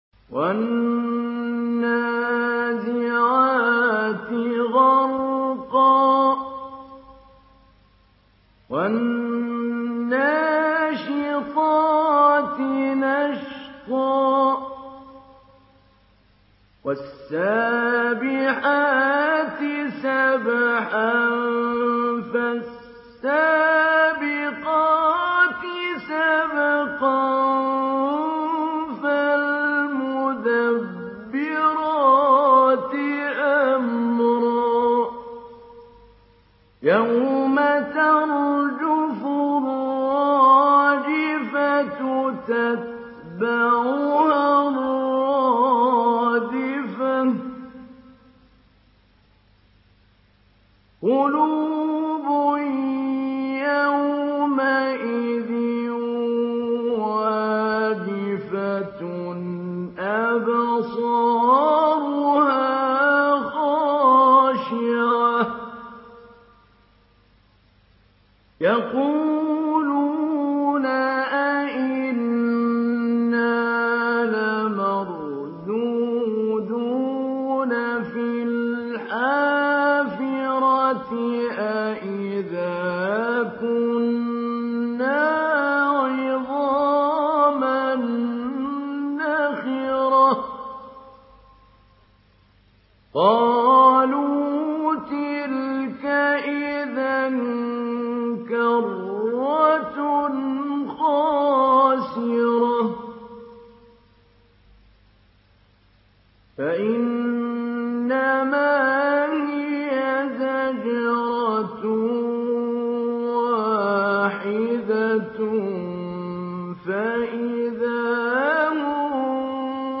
Surah An-Naziat MP3 in the Voice of Mahmoud Ali Albanna Mujawwad in Hafs Narration
Surah An-Naziat MP3 by Mahmoud Ali Albanna Mujawwad in Hafs An Asim narration. Listen and download the full recitation in MP3 format via direct and fast links in multiple qualities to your mobile phone.